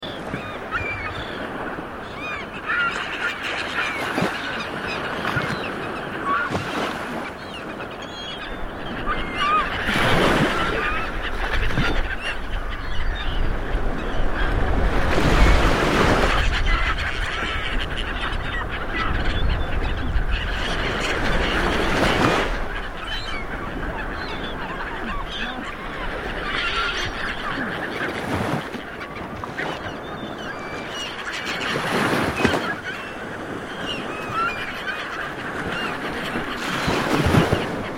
دانلود آهنگ دریا 8 از افکت صوتی طبیعت و محیط
دانلود صدای دریا 8 از ساعد نیوز با لینک مستقیم و کیفیت بالا
جلوه های صوتی